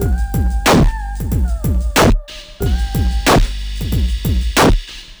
BEAT 2 9201L.wav